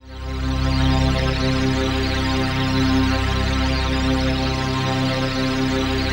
PHASE-PAD 02
PHASEPAD02-LR.wav